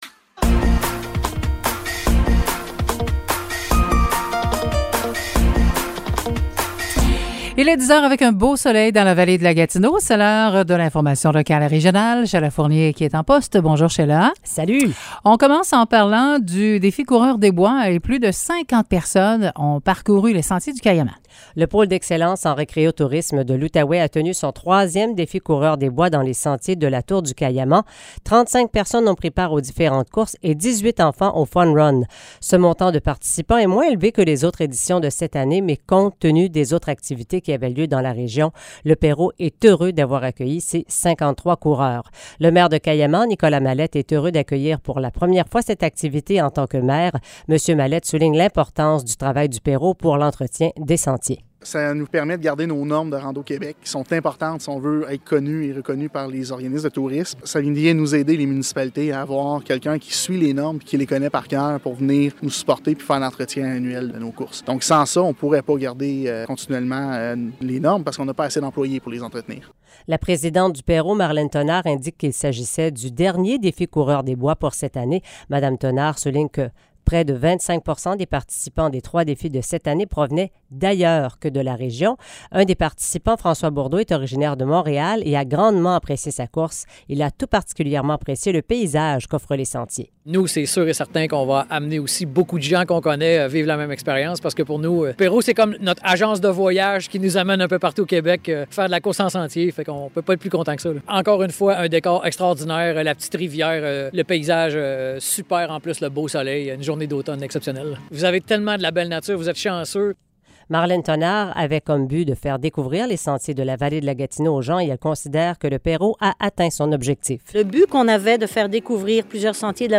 Nouvelles locales - 3 octobre 2022 - 10 h